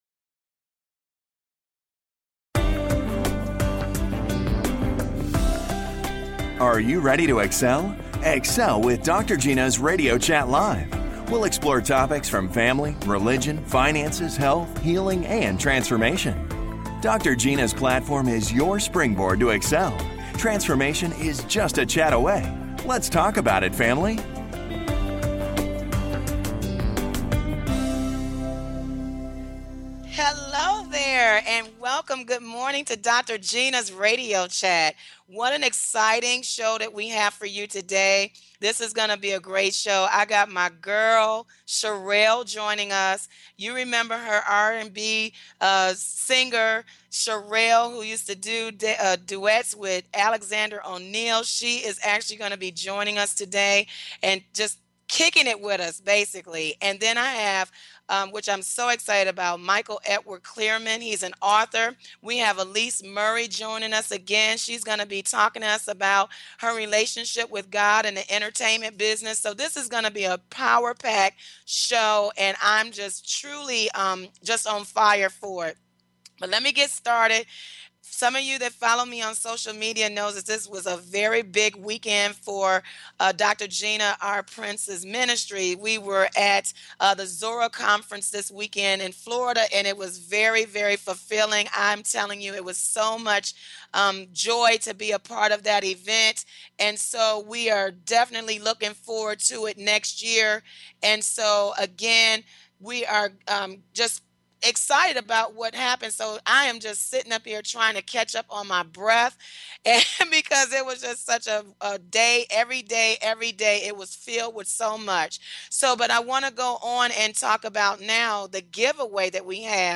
Talk Show
And full of laughter!